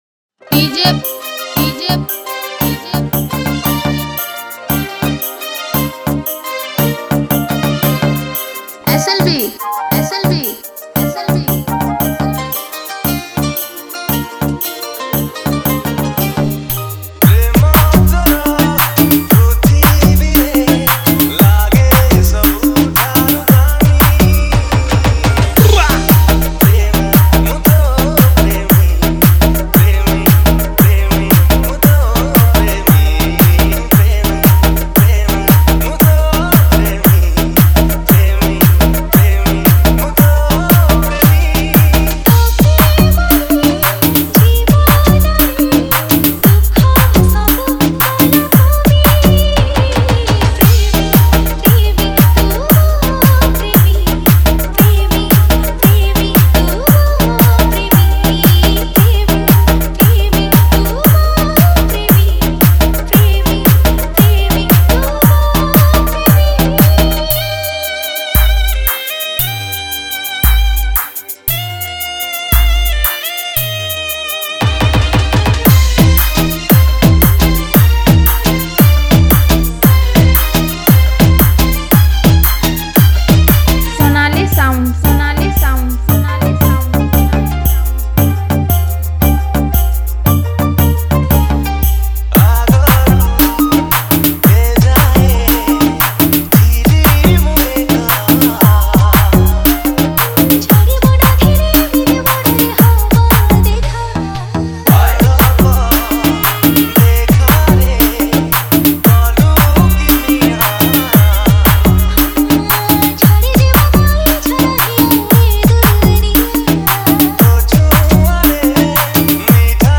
Category:  New Odia Dj Song 2020